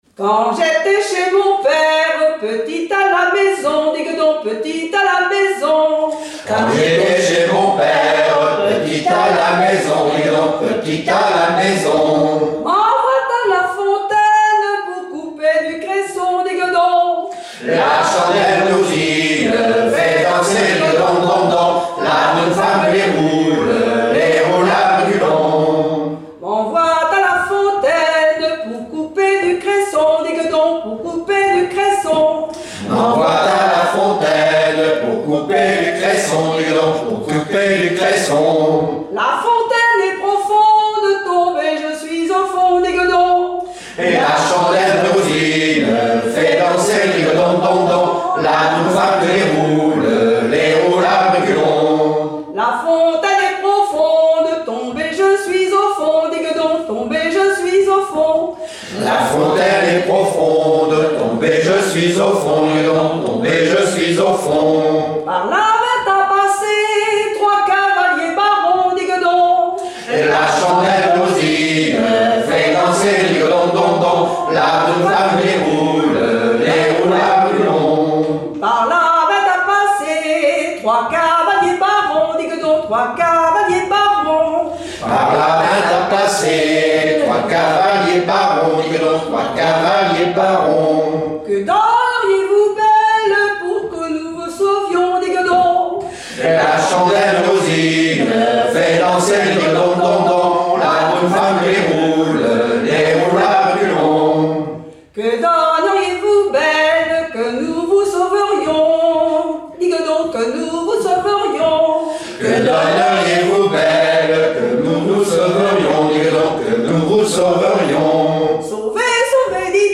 Mémoires et Patrimoines vivants - RaddO est une base de données d'archives iconographiques et sonores.
danse : ronde : rond de l'Île d'Yeu
répertoire de chansons populaires islaises
Pièce musicale inédite